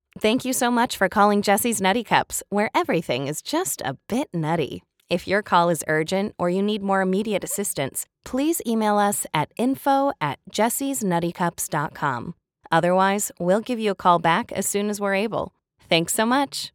Démo commerciale
Microphone : Sennheiser 416, Rode NT-1, Apogee Hype Mic
Cabine : StudioBricks